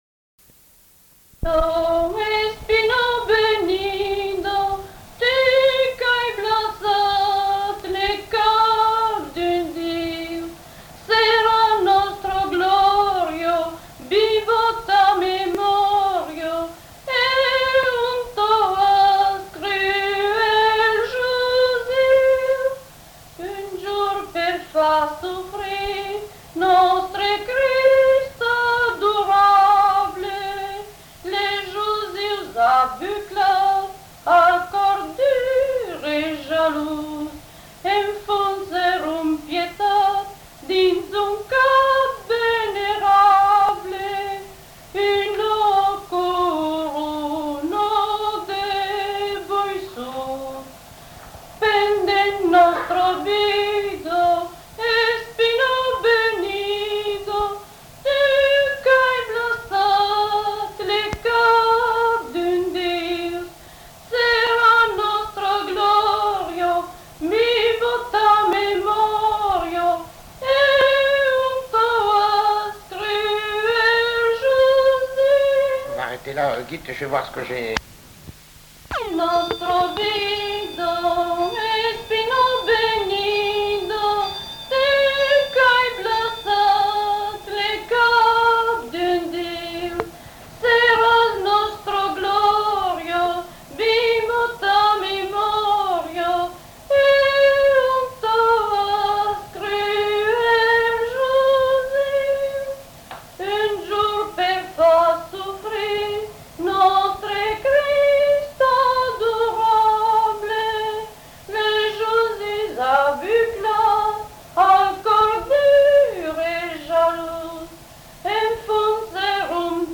Lieu : Mas-Cabardès
Genre : chant
Type de voix : voix de femme Production du son : chanté